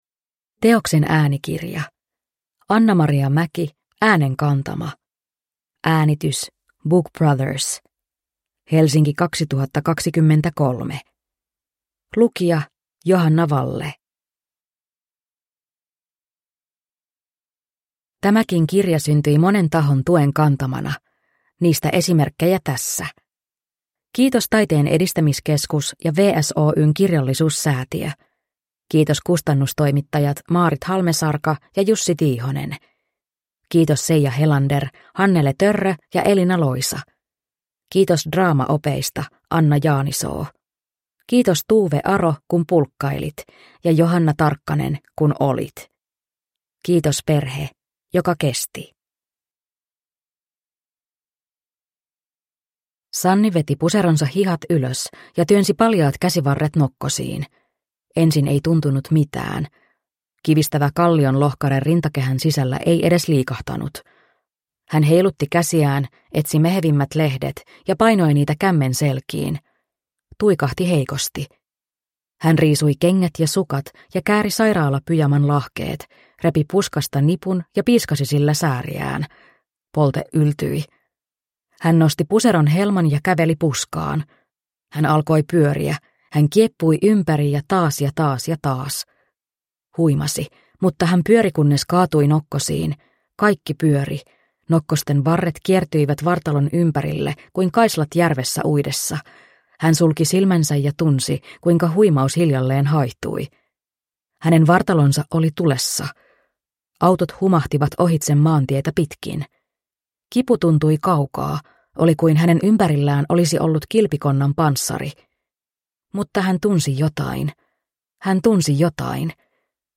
Äänenkantama – Ljudbok – Laddas ner